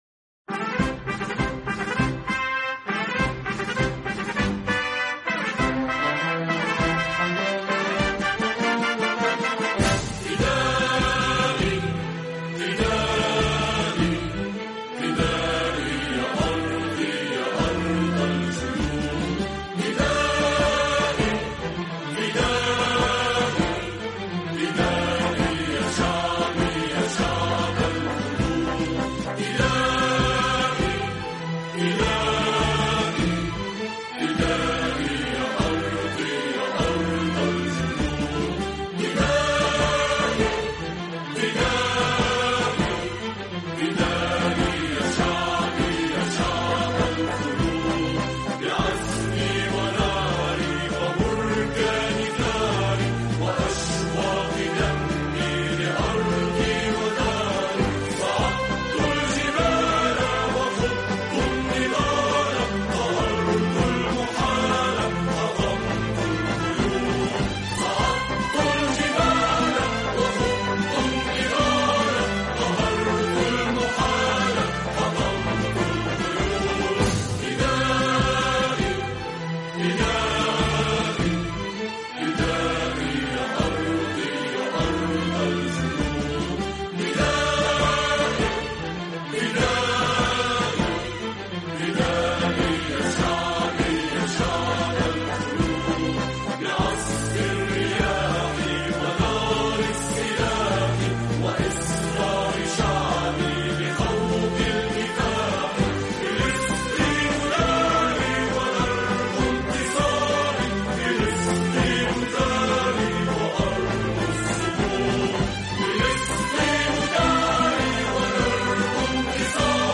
National_Anthem_of_Palestine_(Vocal).mp3